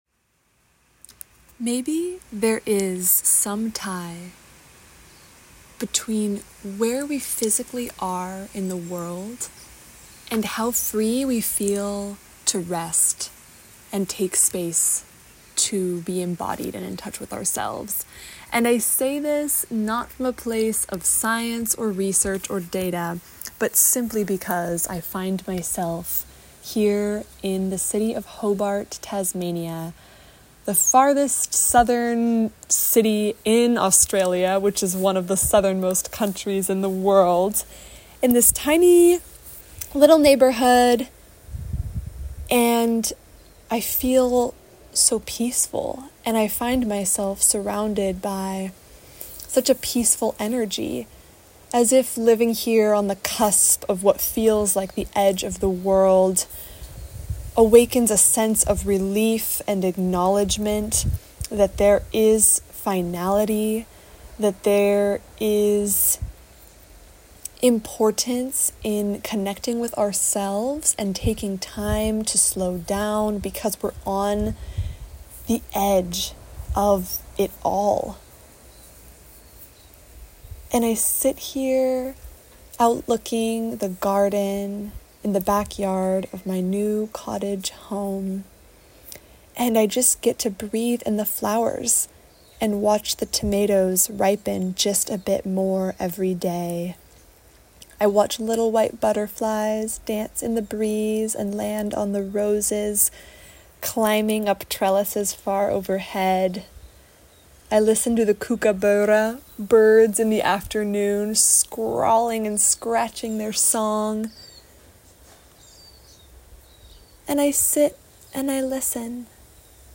One final offering: here is an impromptu poetic recording made sitting on the porch, listening to the birds. I talk here about how embracing a new environment has helped me slow down and connect more with myself.
I loved listening to your calm voice, sharing about peace, freedom and self love.